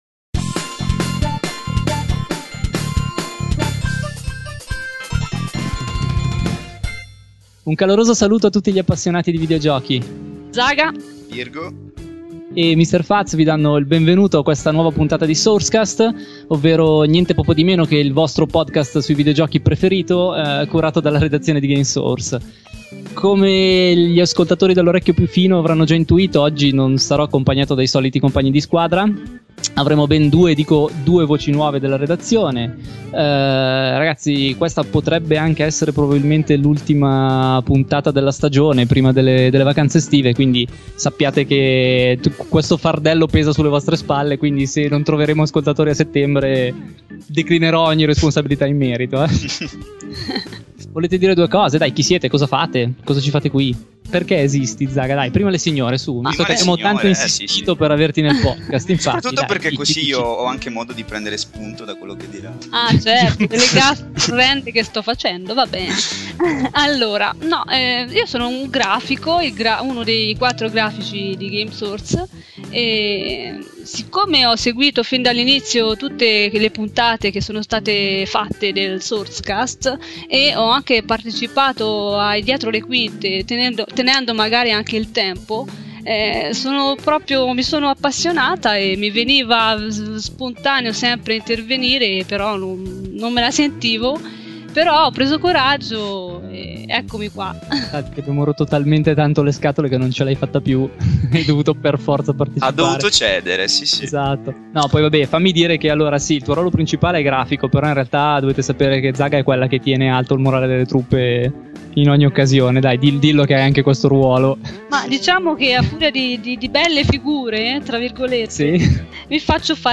In questa nuova chiacchierata